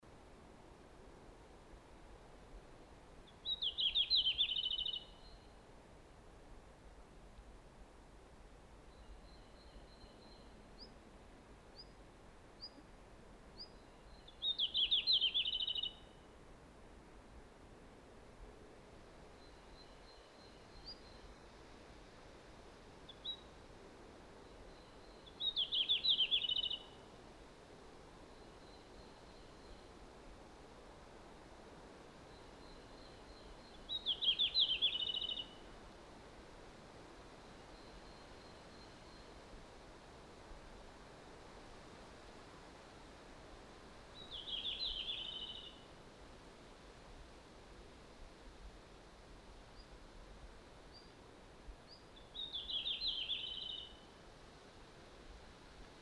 Opi tunnistamaan vuoden vanha sinipyrstökoiras ja sen laulu
Sinipyrstön helposti tunnistettavan ja voimakkaan laulun äänittäminen onnistui sen sijaan useamman kerran. Mikä parasta sinipyrstö oli yhden kerran lähimmillään vain vajaan parinkymmenen metrin päässä mikrofonista.
Suonenjoen Ilmakkamäen sinipyrstön paikka kartalla.